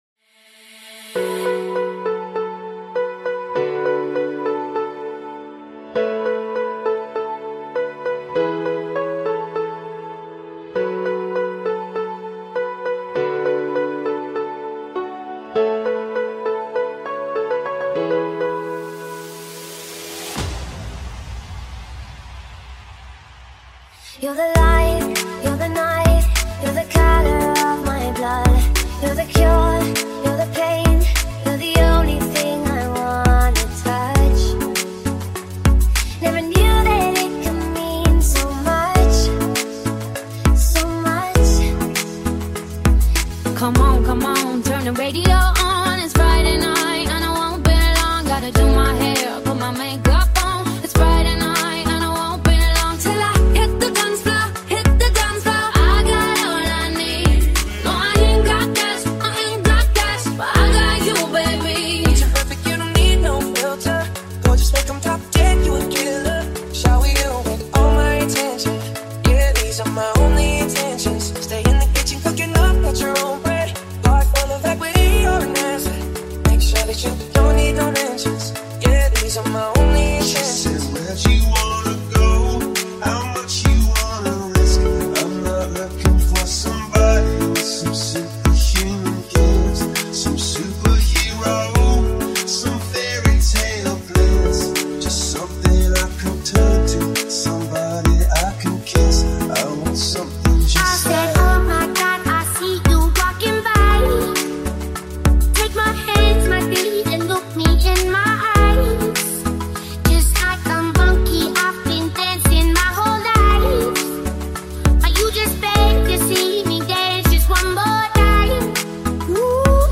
Hollywood Songs Mashup | New Remix Songs